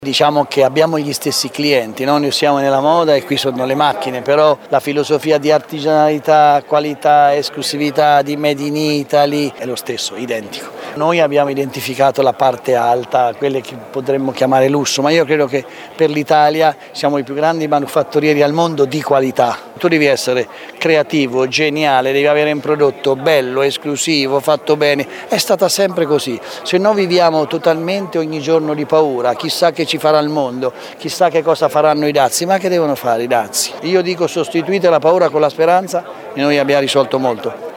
Le interviste ai protagonisti:
Ospite della prima giornata lo stilista Brunello Cucinelli, fondatore dell’omonimo brand di lusso…